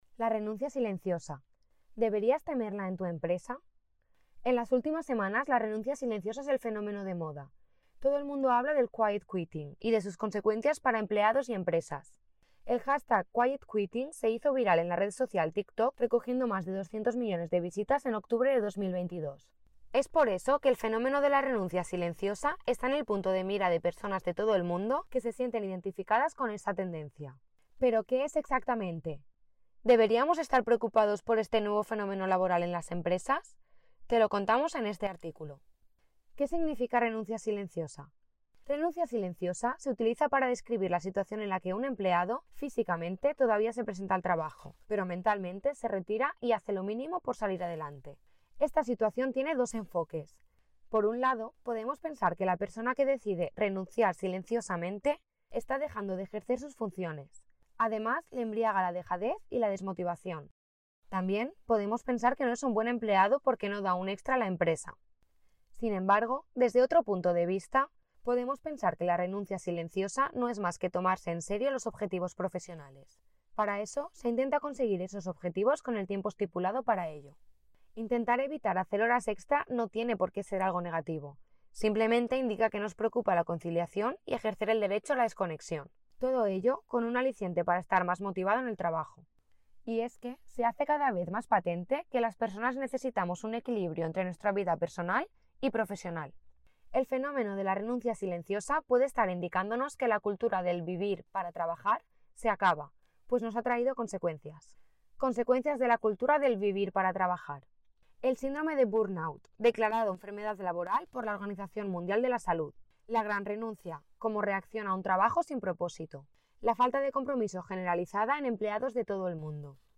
¿No tienes tiempo de leer nuestro artículo? ¡Tranquilo! Nosotros lo hacemos por ti.